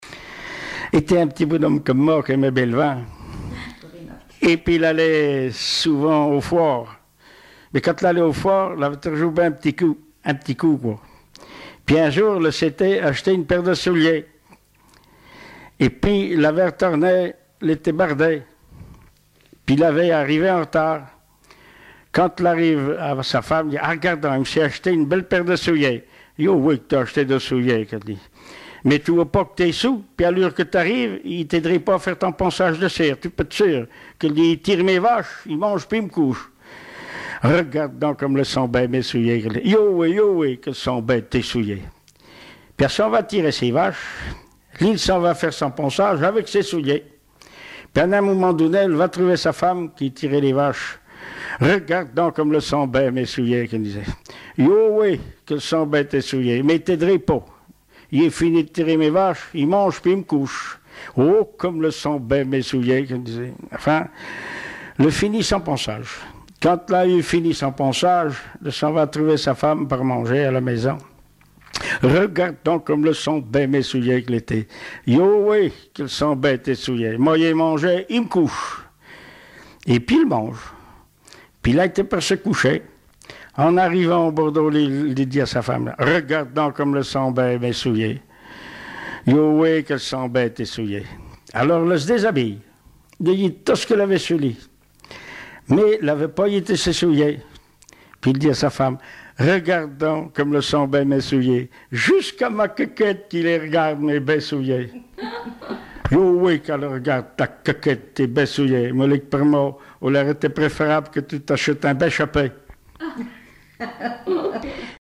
Genre sketch
Témoignages et chansons traditionnelles et populaires
Catégorie Récit